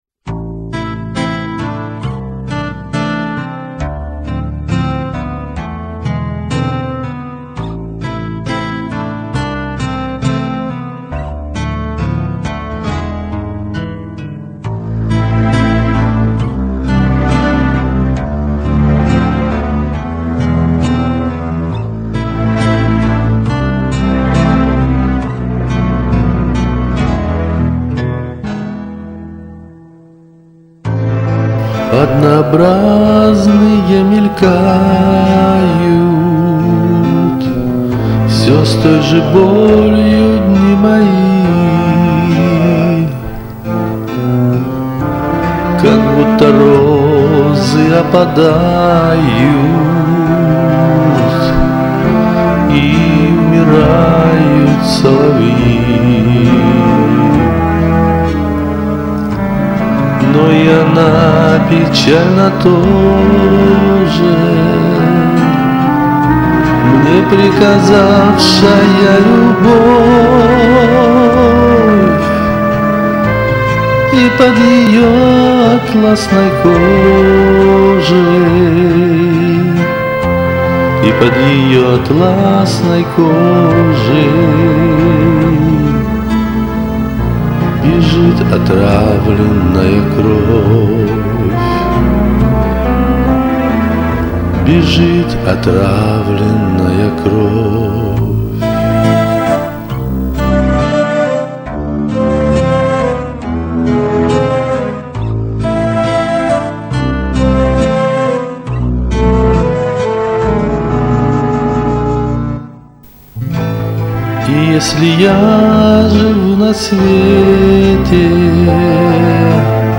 ..это мешает...шумы бы с голосового трека почистить...